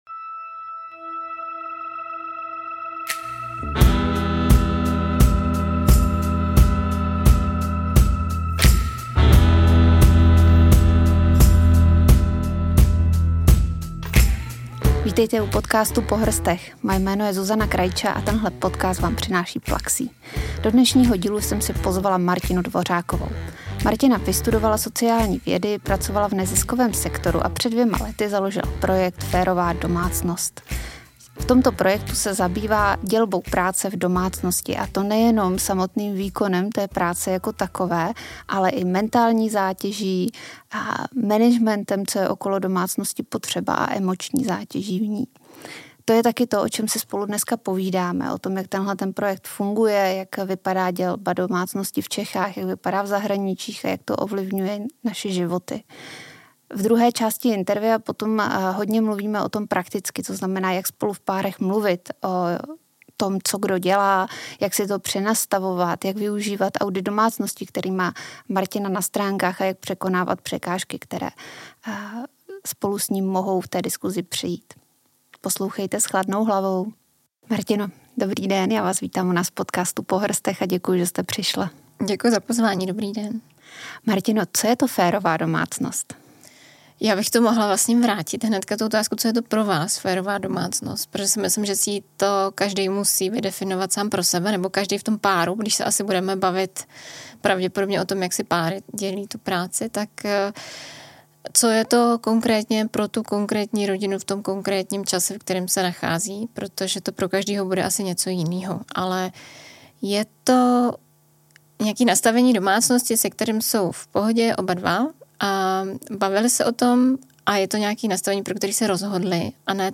Společně se bavíme o tom, jak projekt funguje, jak vypadá rozdělení domácí práce v Česku a v zahraničí a jaký dopad má toto rozdělení na naše každodenní životy. Ve druhé části rozhovoru se zaměřujeme na praktické tipy – jak v páru mluvit o tom, kdo co dělá, jak si nastavit férovější fungování.